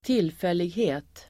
Uttal: [²t'il:fel:ighe:t]